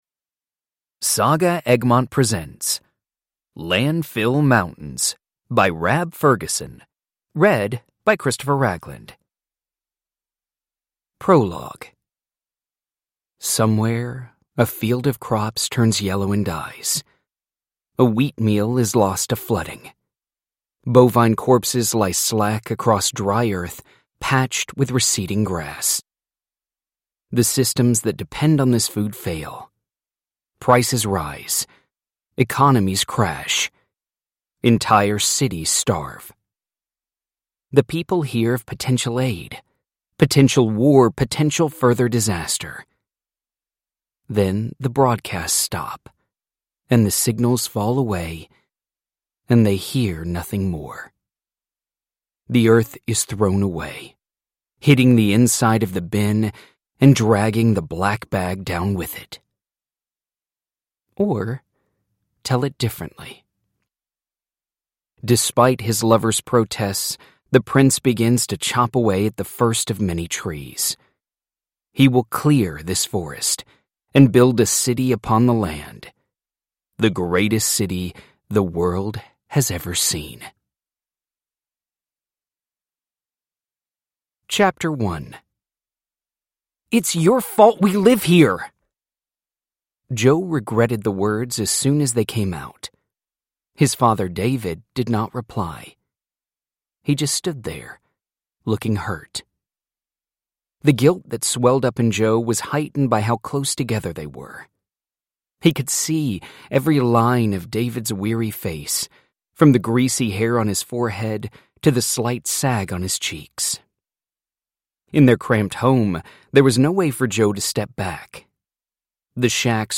Landfill Mountains: A dystopian cli-fi for young adults – Ljudbok